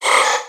Audio / SE / Cries / RALTS.ogg